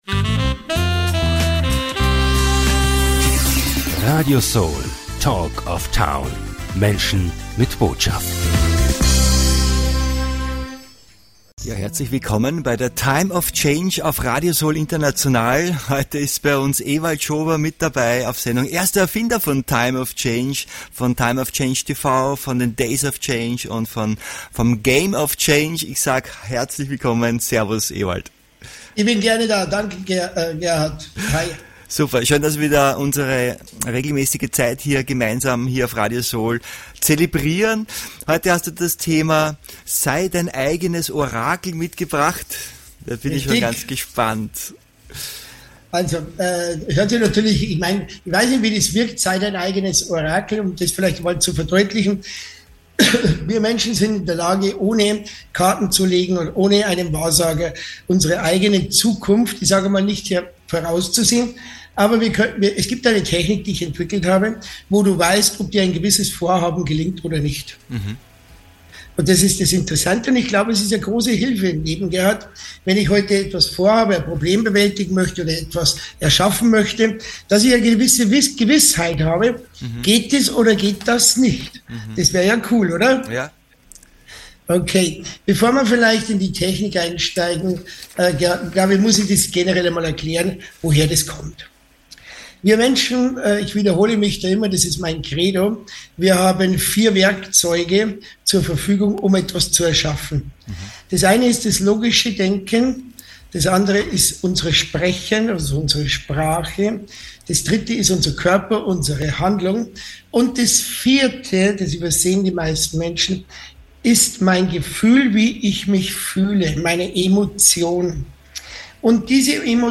Freuen Sie sich auf ein tiefgründiges Gespräch voller inspirierender Impulse, Denkanstöße und spannender Perspektiven.